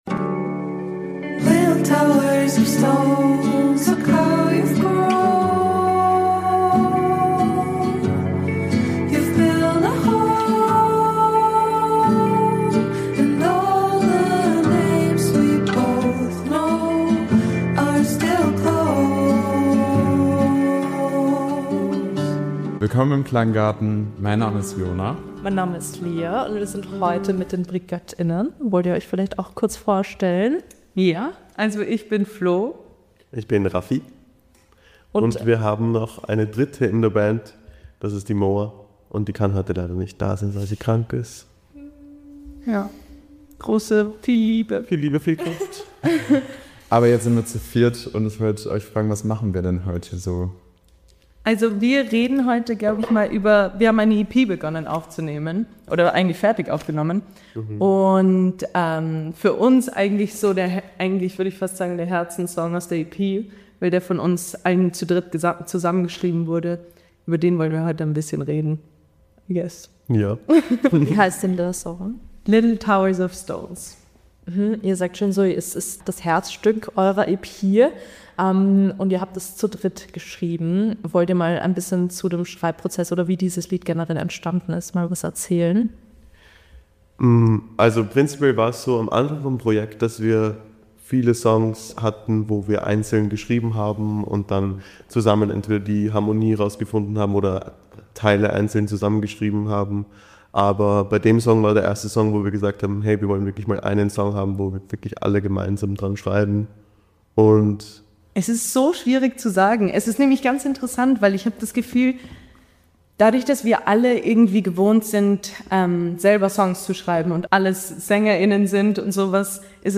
"Little Towers Of Stones" - ein Songgespräch mit Brigött*innen ~ Klanggarten Podcast
Über Kindsein und Altwerden, WG-Leben und Hate von rechten Mackern. Darüber reden wir mit den Brigött*innen.